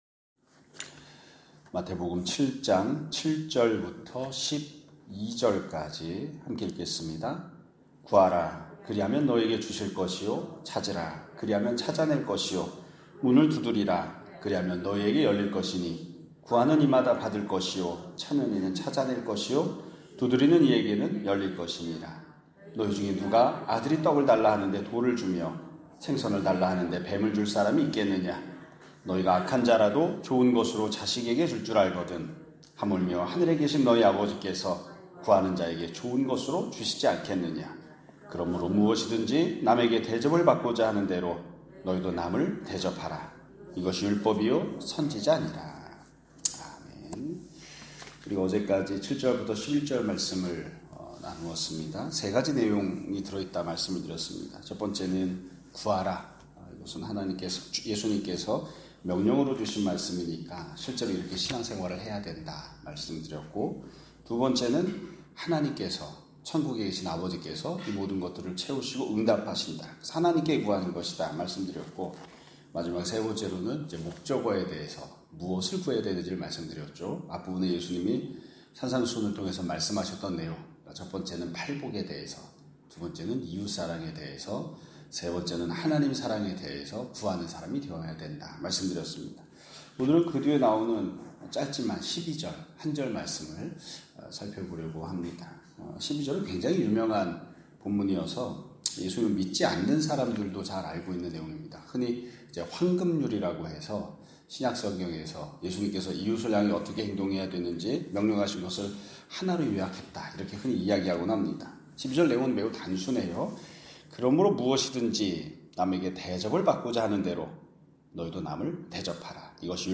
2025년 6월 27일(금요일) <아침예배> 설교입니다.